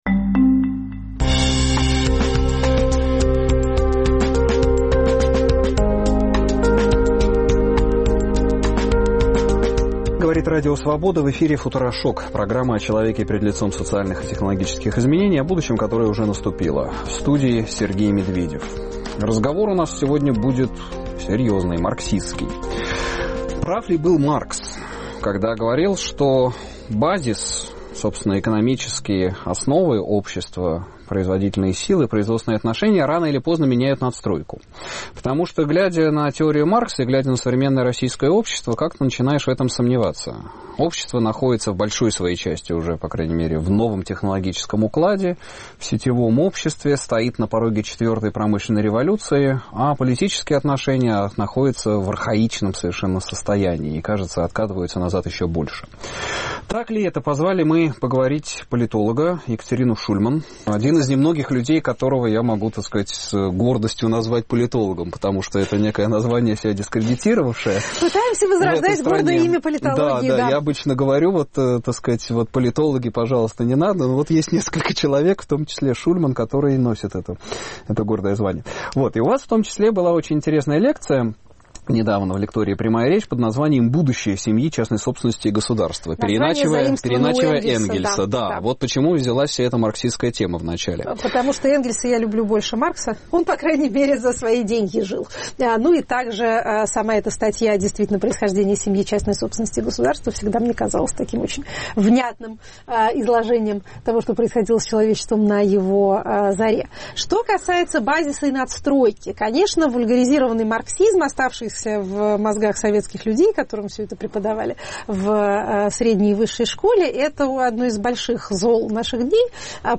Гость: Екатерина Шульман, политолог